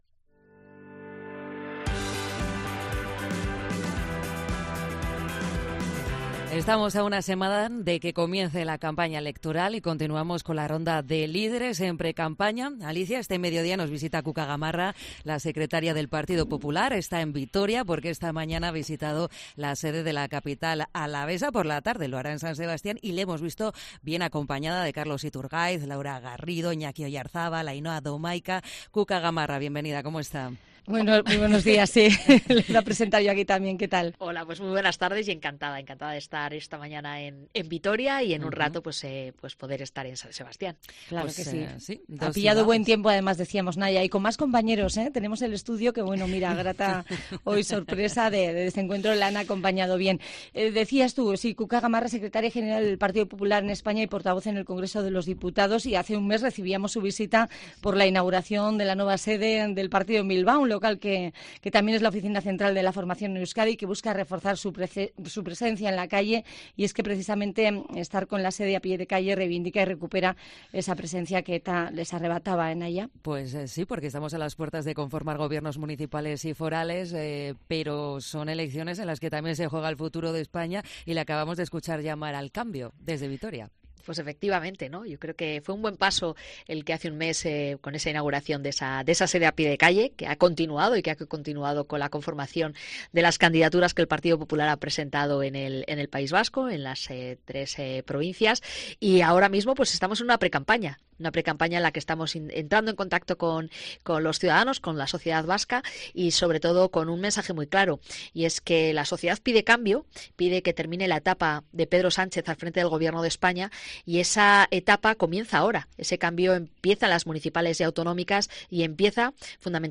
Entrevista a Cuca Gamarra, en COPE Euskadi